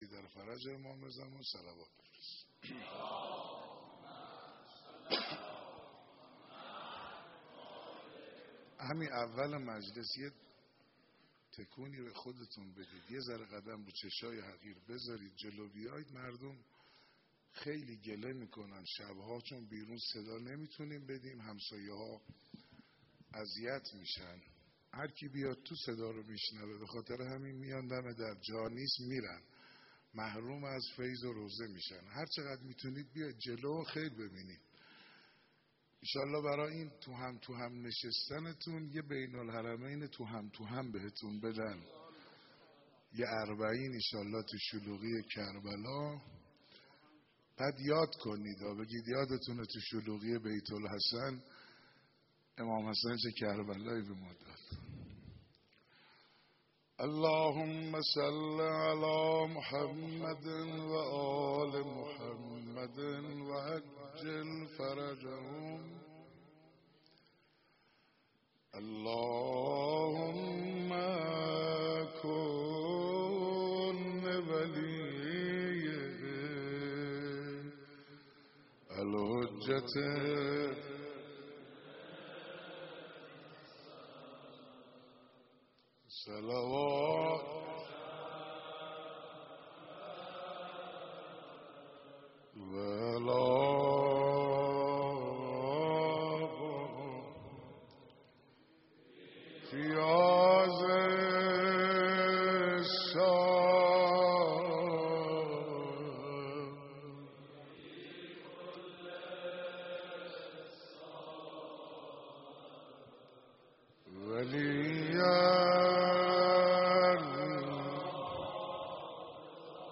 بیت الحسن المجتبی (ع) مراسم شب های ماه مبارک رمضان
مداحی